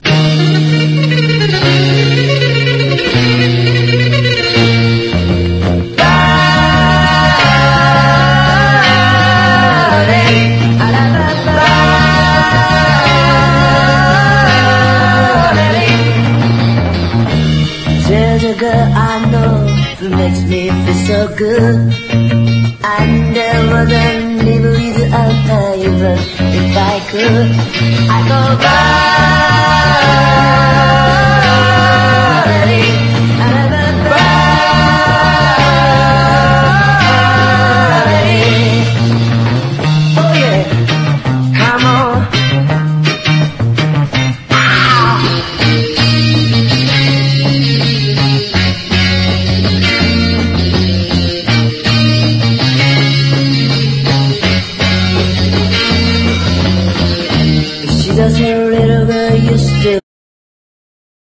EASY LISTENING / VOCAL / JAZZ VOCAL / POPCORN
チャカポコ・ラテン・パーカッションが疾走する
カンパニー・インナースリーヴ付き/コーティング・ジャケット/深溝/US ORIGINAL盤 MONO！